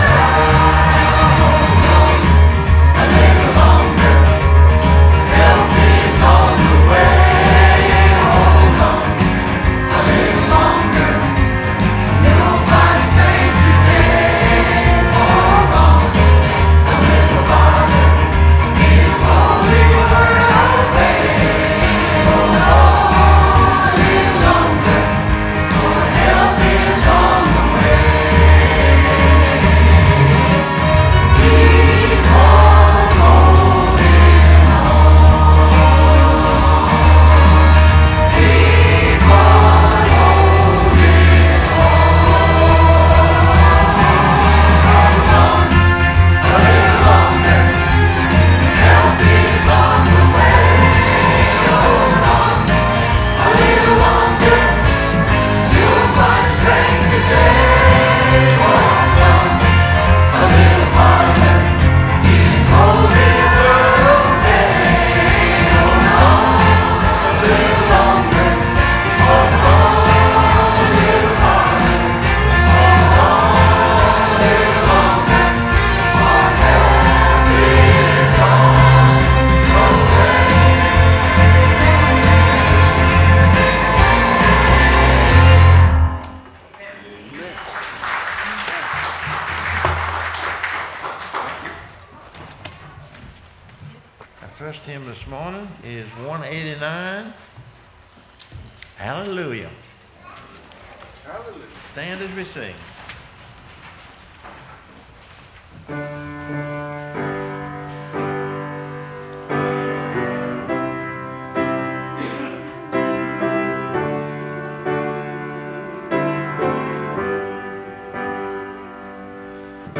sermonJuly27-CD.mp3